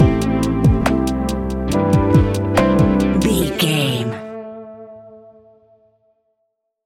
Ionian/Major
A♭
chilled
laid back
Lounge
sparse
new age
chilled electronica
ambient
atmospheric
instrumentals